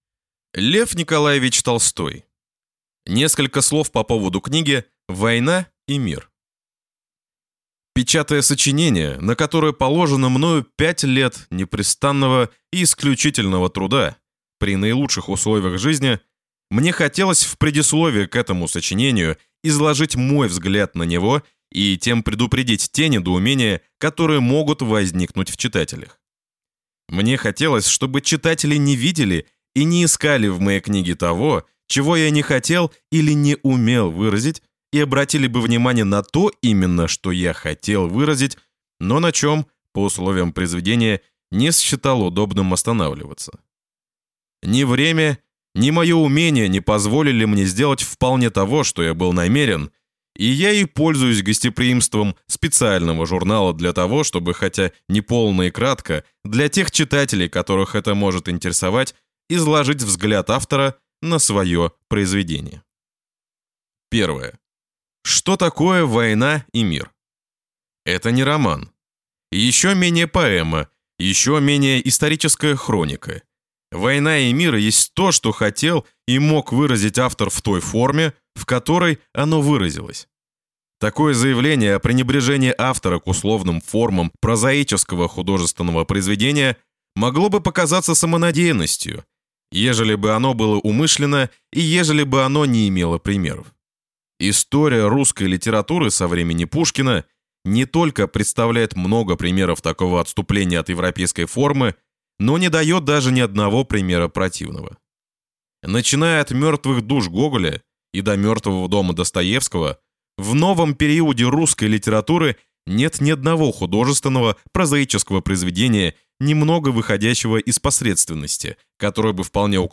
Аудиокнига Несколько слов по поводу книги «Война и мир» | Библиотека аудиокниг